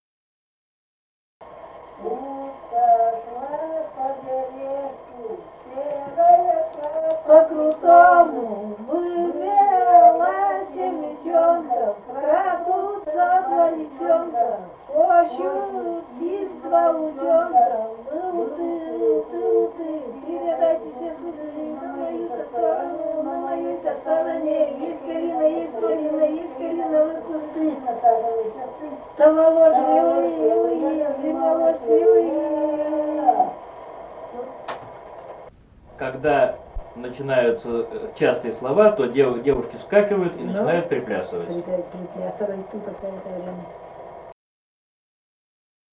Русские песни Алтайского Беловодья 2 «Утка шла по бережку», игровая плясовая.
Республика Казахстан, Восточно- Казахстанская обл., Катон-Карагайский р-н, с. Белое, июль 1978.